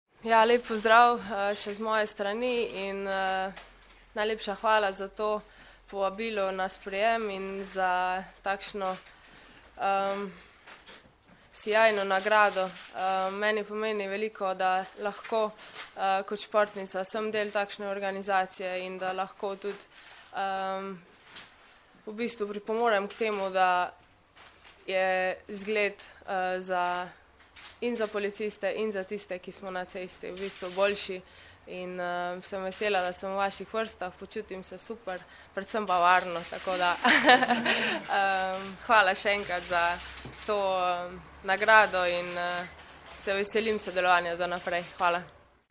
Zvočni posnetek izjave Tine Maze (mp3)